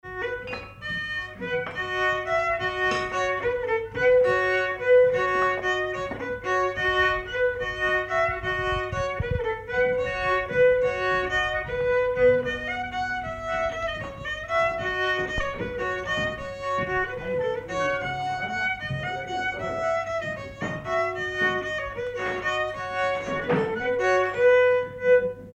Air
Villard-sur-Doron
Pièce musicale inédite